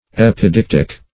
Search Result for " epidictic" : The Collaborative International Dictionary of English v.0.48: Epidictic \Ep`i*dic"tic\, Epidictical \Ep`i*dic"tic*al\, a. [L. epidictius.
epidictic.mp3